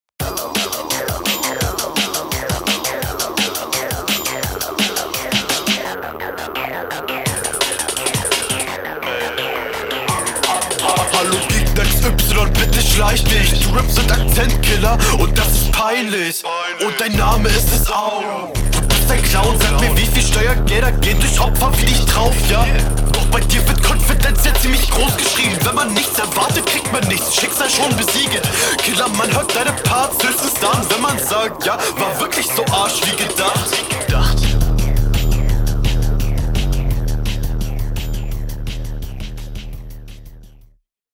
Beat ist ne komplette psychose aber du machst das beste draus